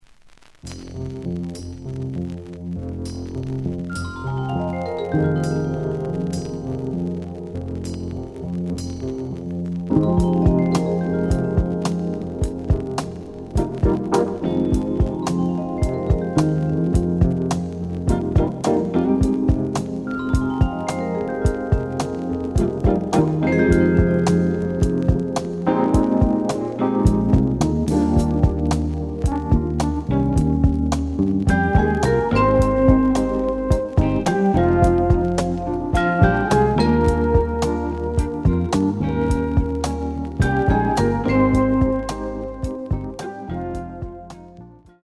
The audio sample is recorded from the actual item.
●Genre: Disco
Looks good, but slight noise on B side.)